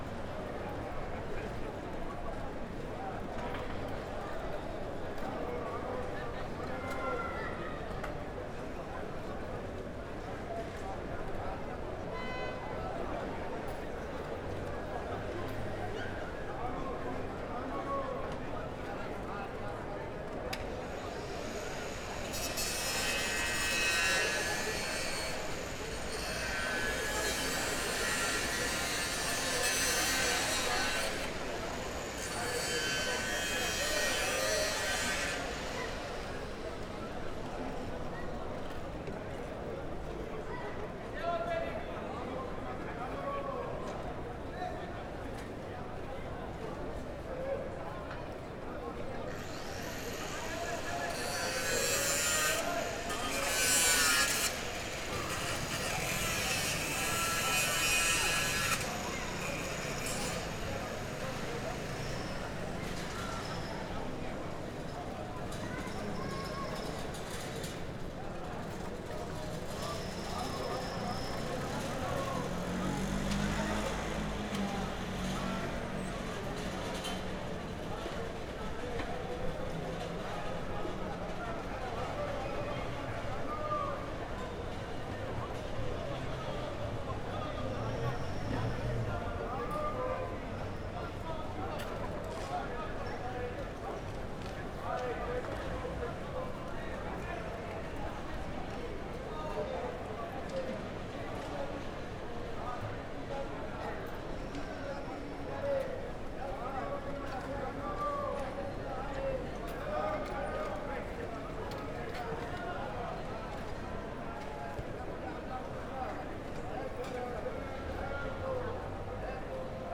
BABEL AMBIANCE MS MARSEILLE MARCHE NOAILLES PIETONS VOIX SCIE CIRCULATION RUMEUR VILLE